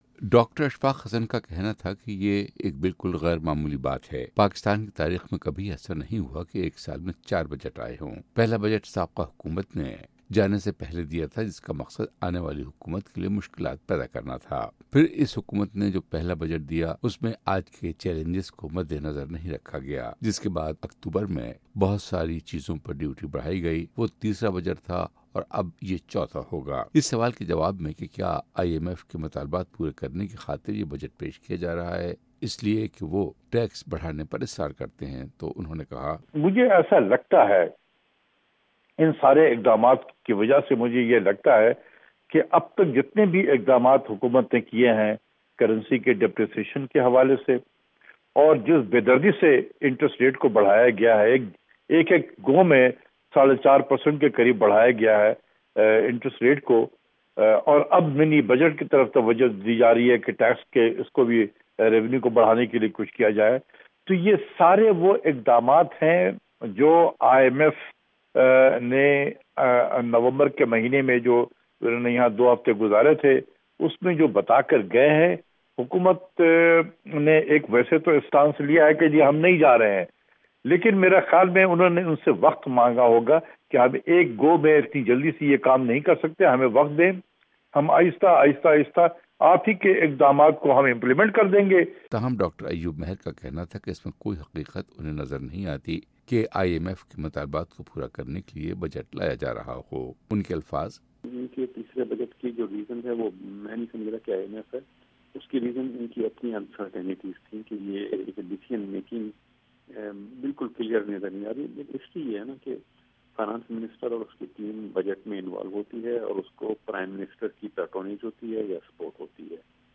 دو اقتصادی ماہرین